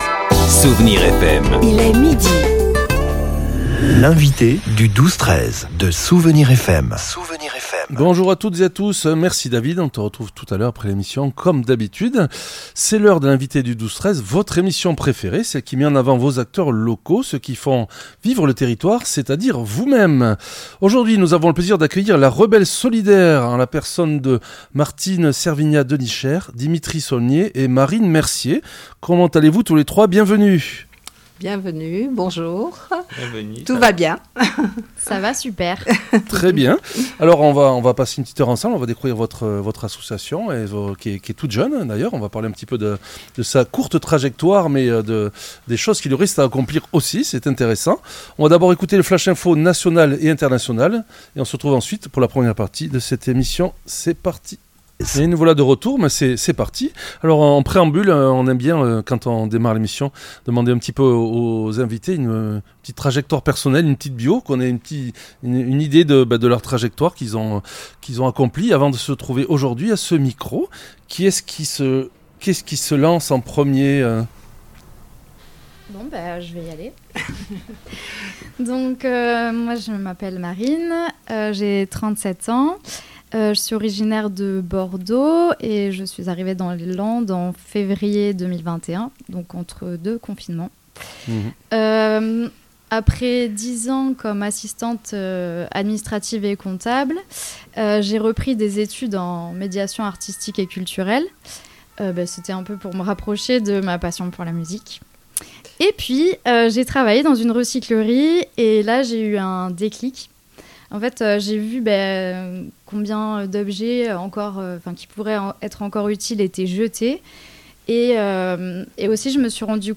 L'entretien a permis de lever le voile sur les besoins concrets de cette structure en plein essor : la recherche d'un local permanent, d'un véhicule utilitaire et de matériel de traçabilité pour professionnaliser la valorisation des dons.